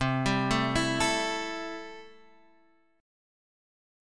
C6 Chord
Listen to C6 strummed